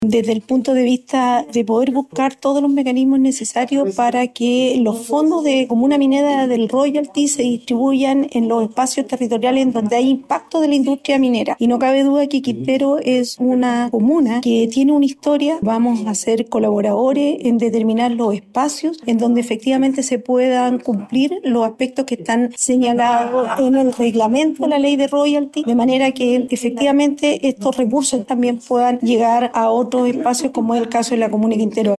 En este sentido, la ministra de Minería, Aurora Williams, reconoció que Quintero tiene una historia en cuanto a los impactos generados por la actividad minera, y señaló que serán colaboradores para determinar los espacios en donde se cumpla la normativa.